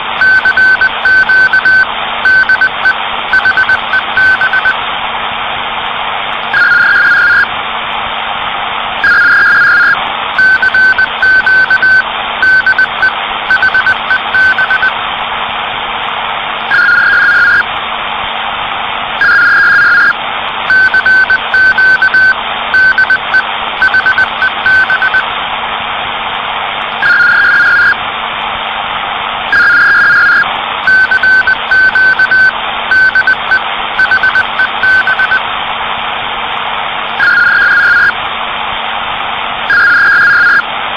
Gemischter Morse/PACTOR-CQ-Ruf um 2010
CW_PACTOR_CQ_de_HEB.mp3